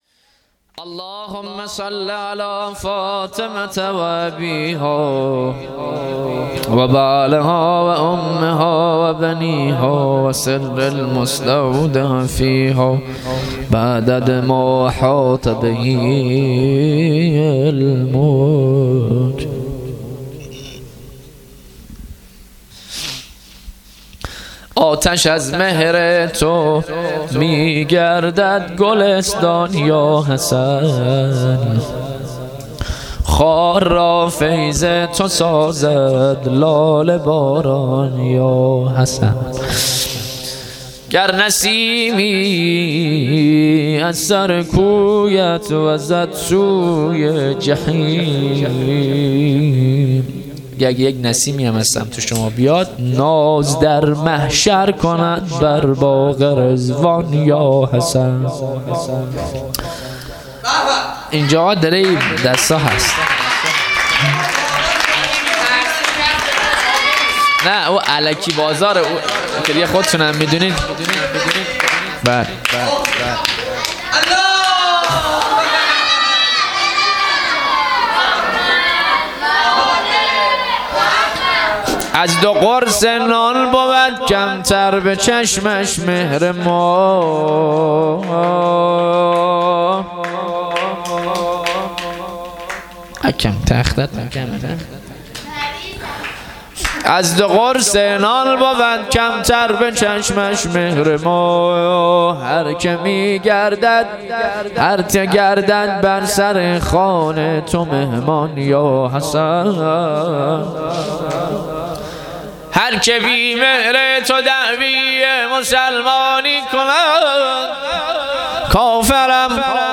مدح اهل بیت میلاد امام حسن علیه السلام هیئت هفتگی‌ انصار السلاله